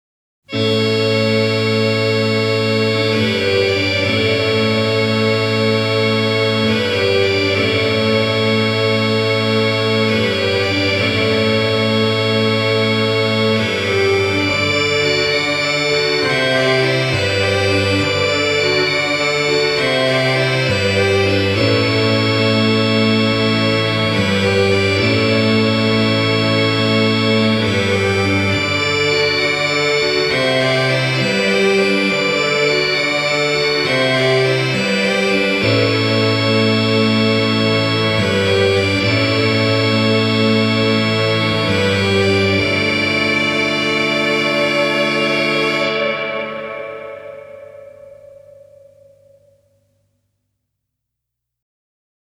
Genre: Indie Pop-Rock / Psychedelic Rock